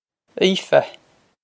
Aoife Ee-fuh
Pronunciation for how to say
Ee-fuh
This is an approximate phonetic pronunciation of the phrase.